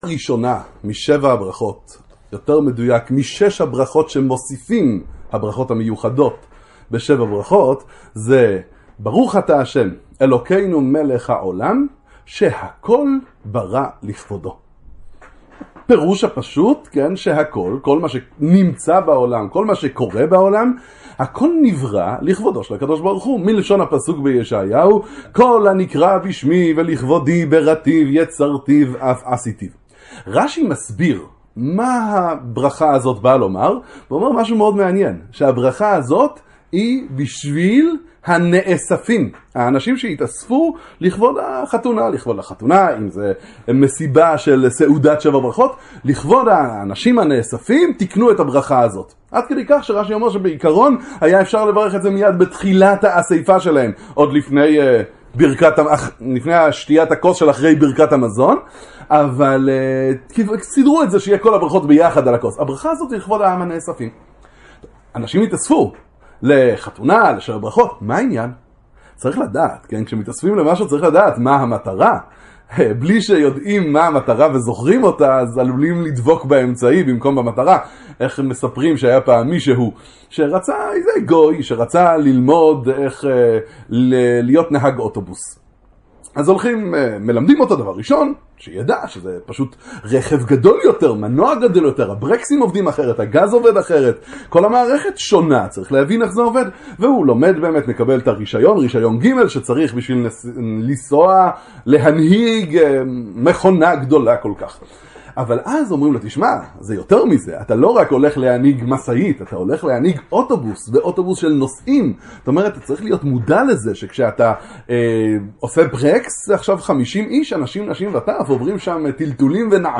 דרשה לשבע ברכות